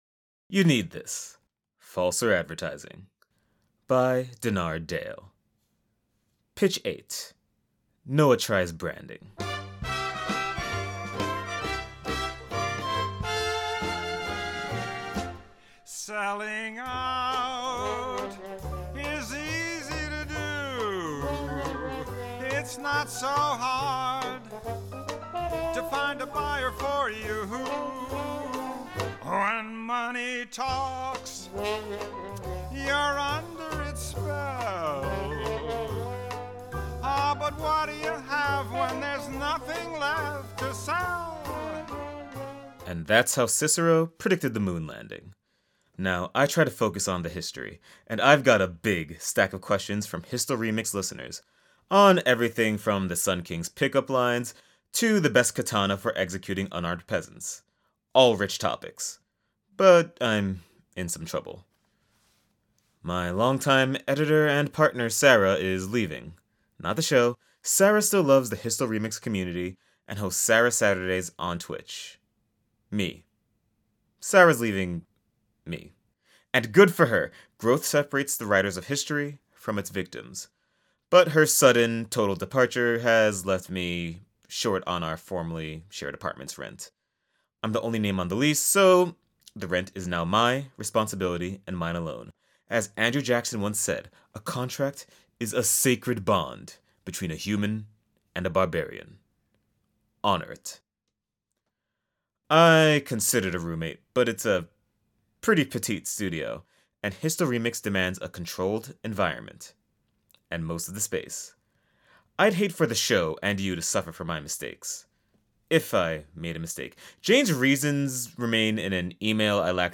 reads fake ads that feel too real